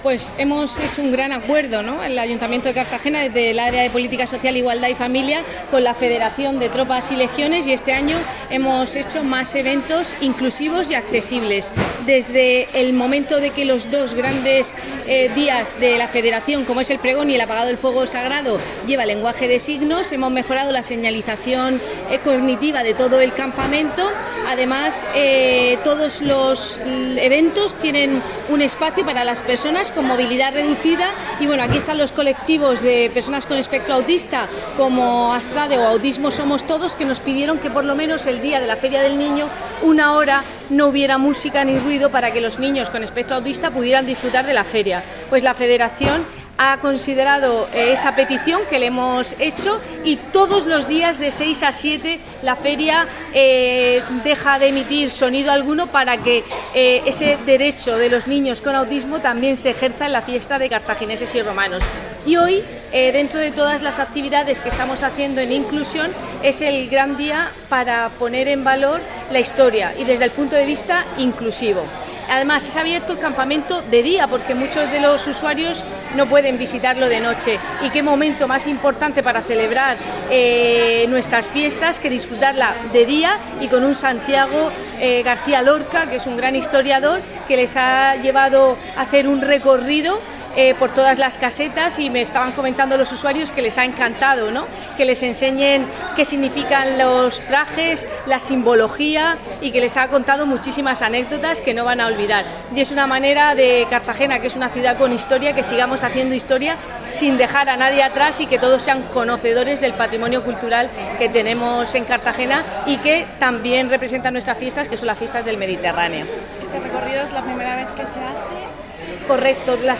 Enlace a Declaraciones de la concejala del área de Política Social, Igualdad y Familia, Cristina Mora.